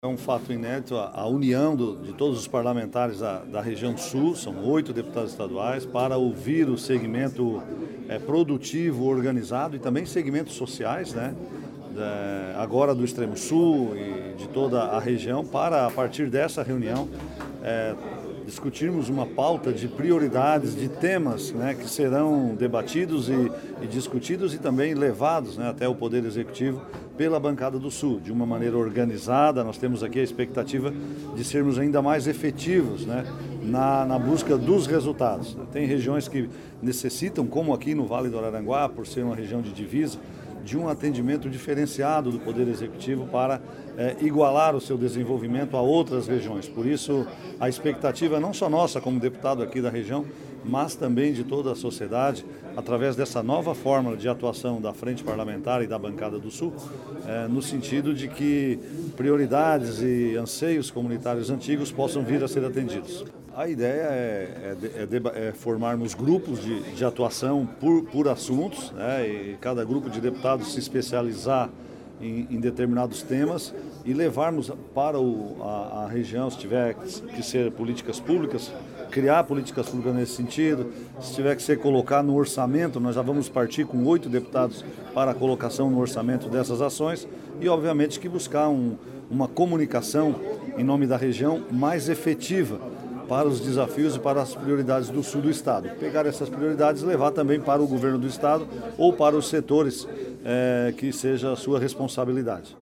Ouça os parlamentares que participaram dos encontros: